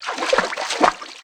MISC Water, Splash 01.wav